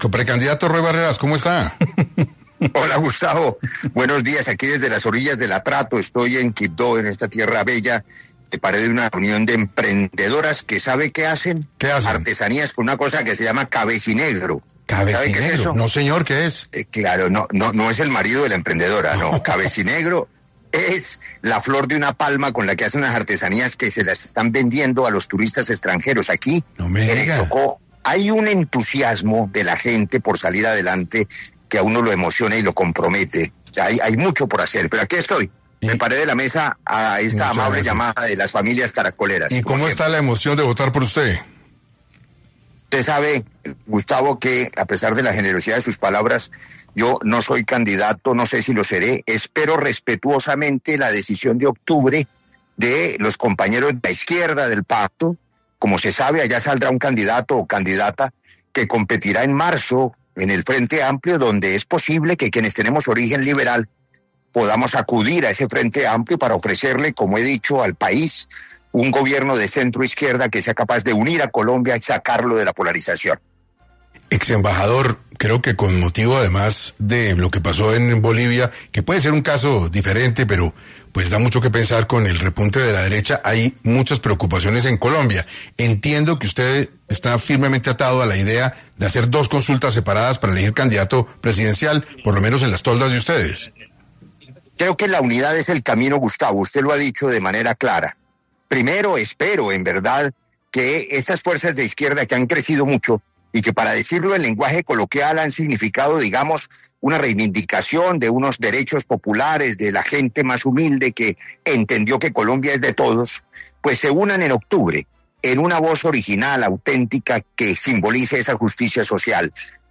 En Caracol Radio estuvo Roy Barreras conversando sobre sus posibles intenciones de ser el siguiente en llegar a la Casa de Nariño